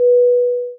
Airplane PA Sound 2
airline airplane call flight-attendant PA Public-Address sound effect free sound royalty free Memes